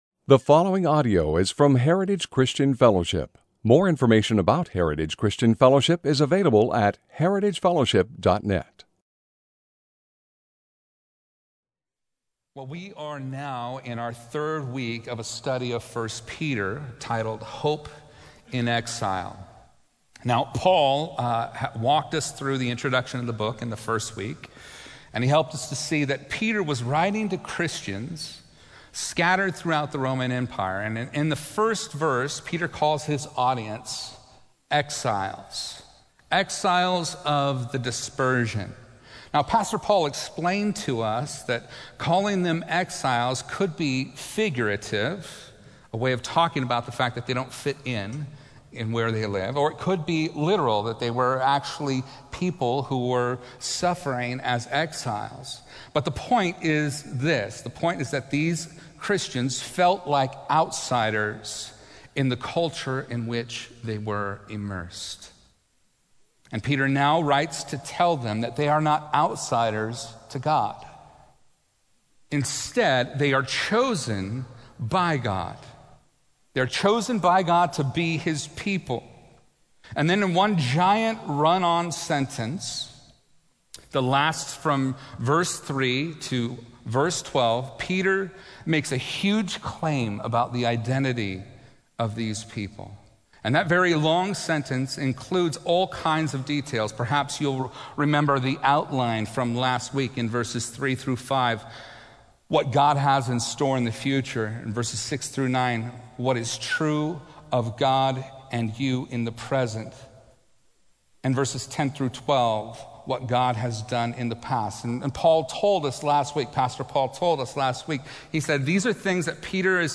A message from the series "Hope In Exile." 1 Peter 5:1-5